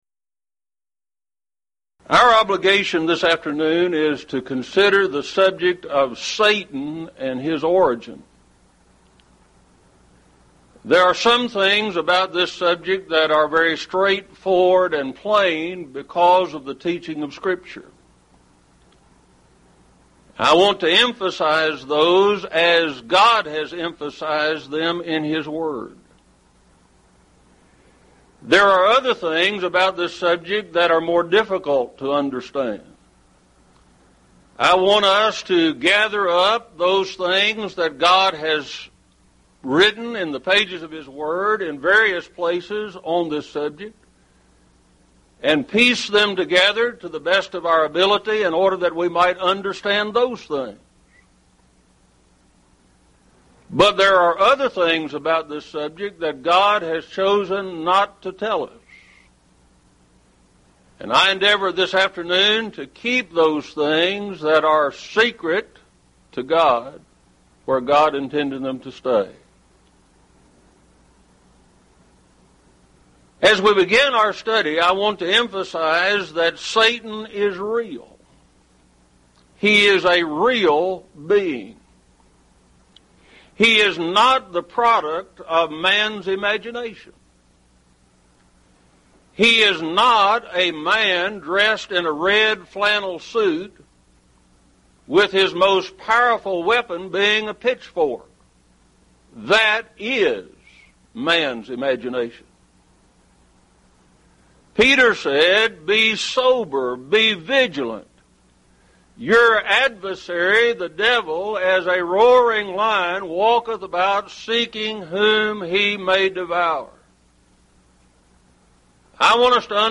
Event: 1st Annual Lubbock Lectures